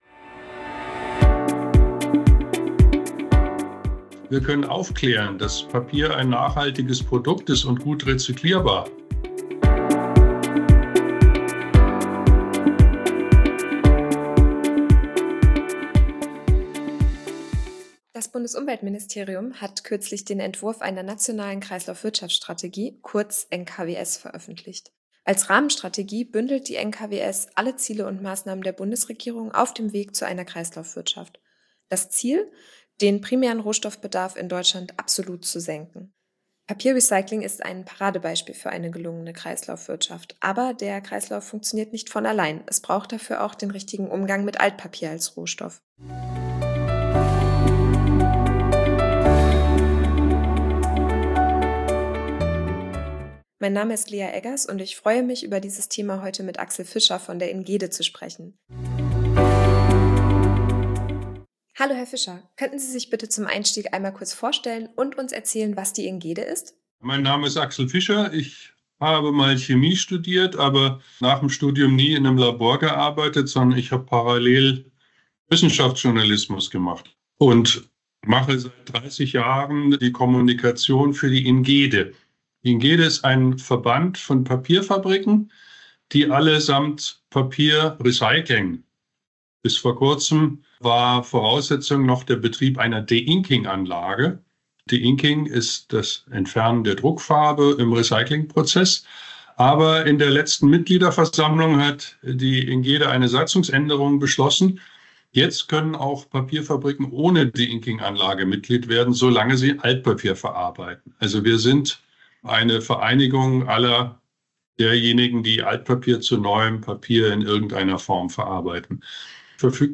im Interview.